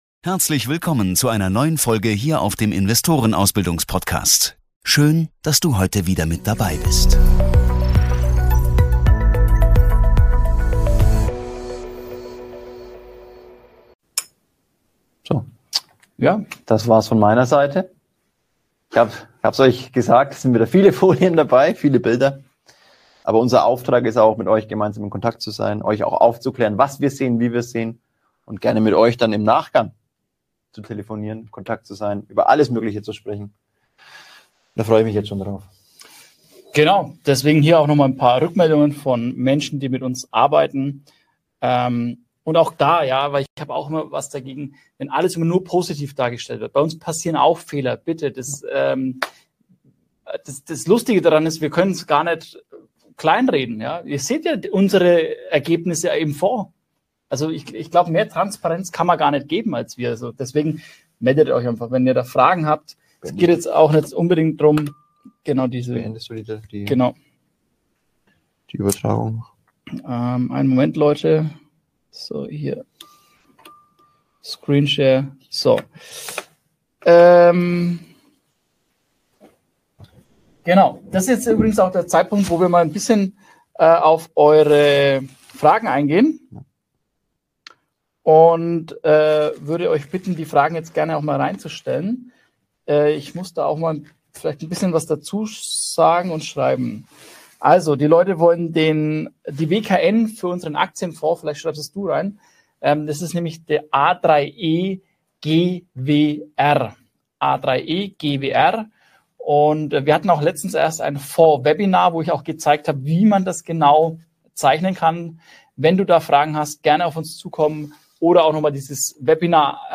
In dieser ausführlichen Fragerunde beantworten wir die wichtigsten Fragen unserer Community. Wir geben unsere Einschätzung zu den aktuellen Entwicklungen bei Gold, Silber und Bitcoin und erläutern unsere Perspektive auf die Märkte.